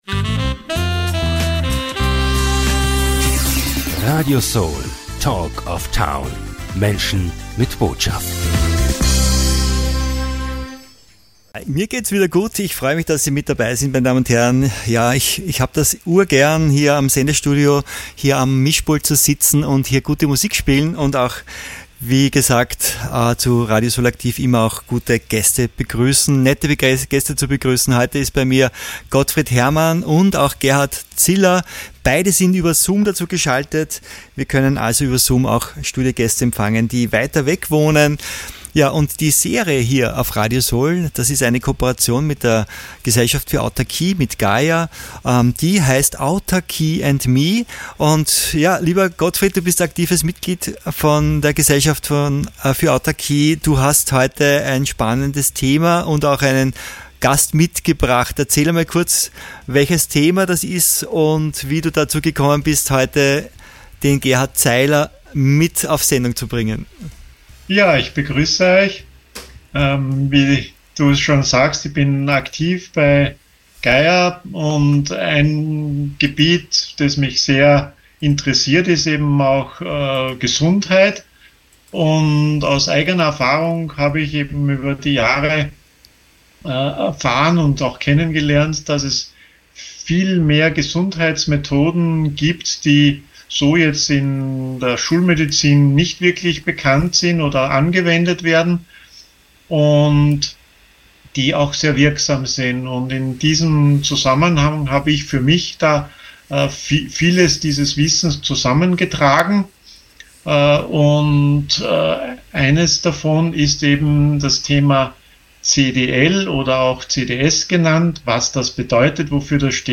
Was ist Chlordioxid und ist es giftig? Unterschied Chlordioxid zu Chlorbleiche? Wirkungsweisen und Einsatz von Chlordioxid - Kontraindikationen und Nebenwirkungen erfahren Sie in diesem Interview.